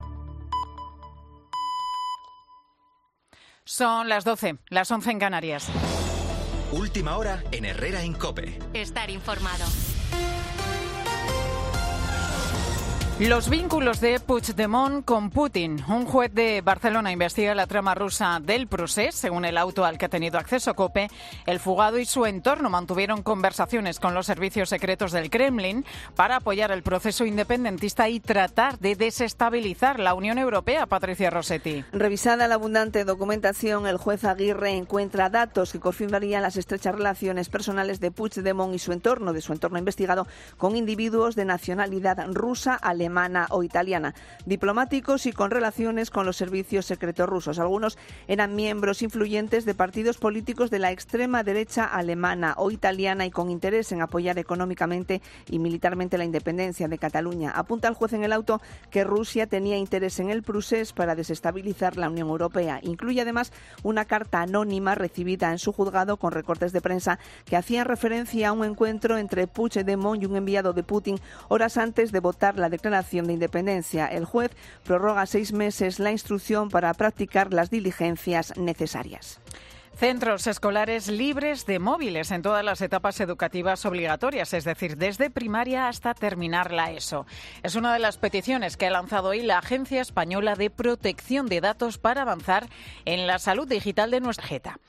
Boletín